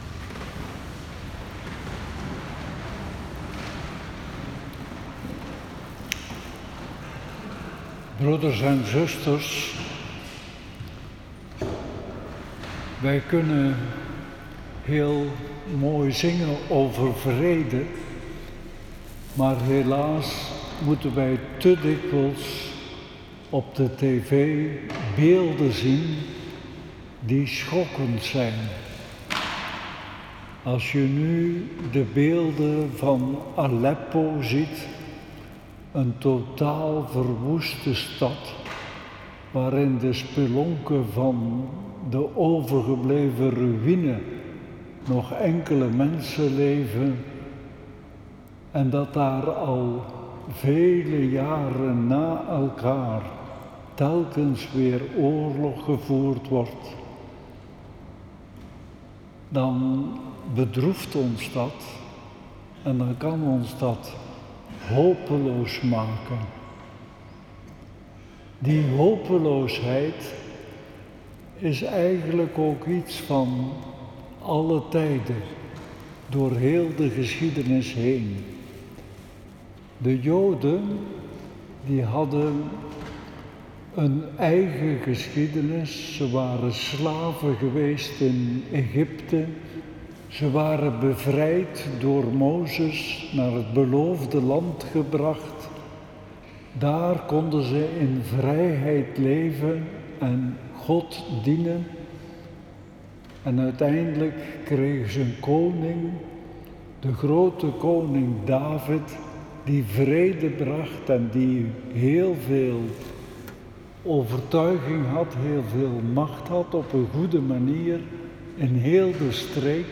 Viering 25 december 2019
Preek.m4a